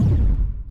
Techmino/media/effect/chiptune/emit.ogg at 65c050f6df9867f19b509d00a08efd7ffad63b17
emit.ogg